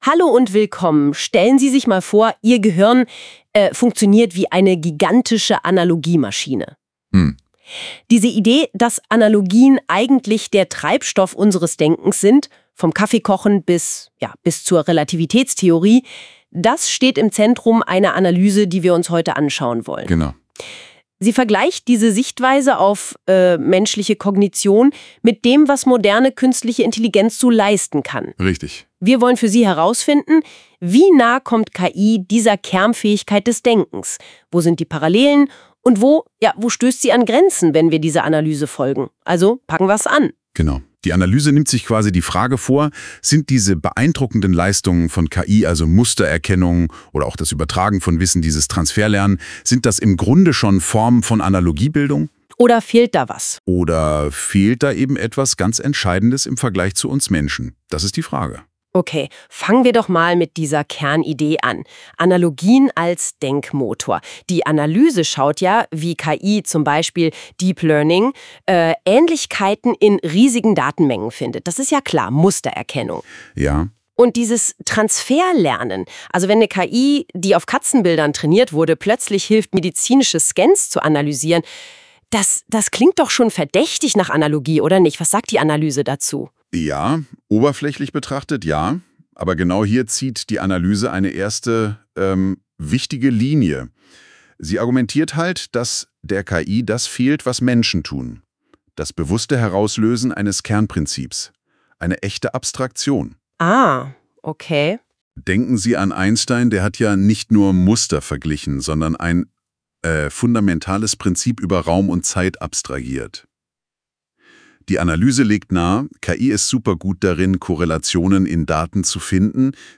Als automatisch ersteller Podacst, in dem die Frage diskutiert wird, inwieweit sich die Analogie-These auf die moderne KI übertragen lässt.